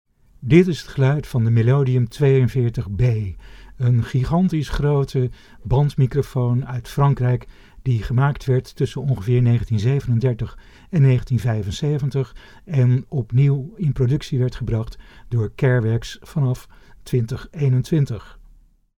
Het geluid is vergelijkbaar maar enigszins anders, waardoor hij favoriet is voor sommigen in opnamestudio's. Beide microfoons hebben een enorm nabijheidseffect; lage frequenties worden overdreven geaccentueerd wanneer ze vlak bij een geluidsbron worden gebruikt, wanneer ze verder weg worden geplaatst (0,5 m of meer), heeft de 42B iets meer gedefinieerde lage tonen en meer afgeronde hoge tonen.
Melodium 42B sound NL.mp3